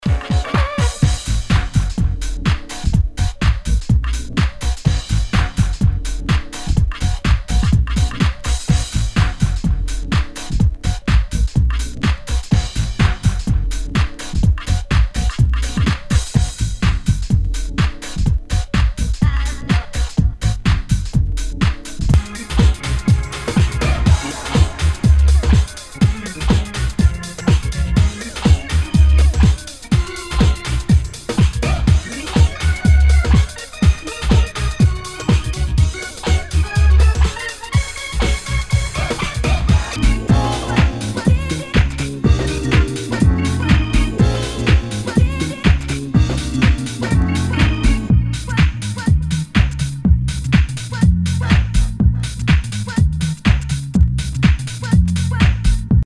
HOUSE/TECHNO/ELECTRO
ナイス！ファンキー・ハウス！
全体にチリノイズが入ります。